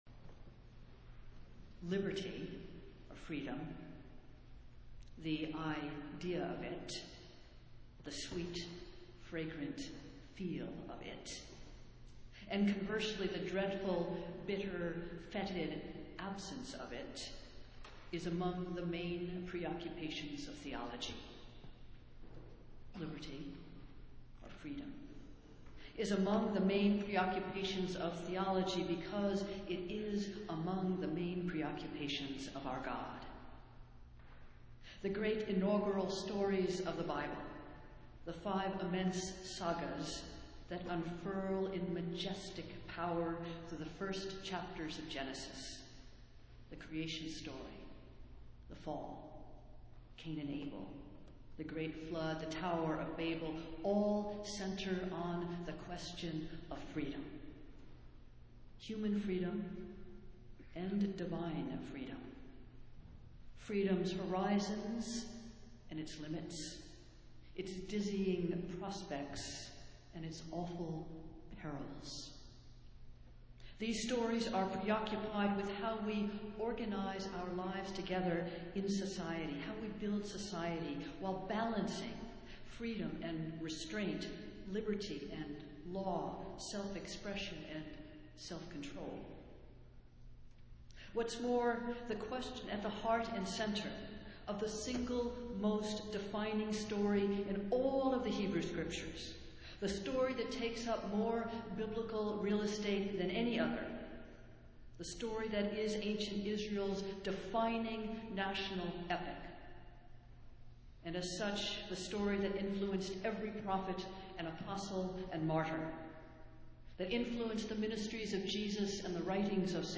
Festival Worship - Fifteenth Sunday after Pentecost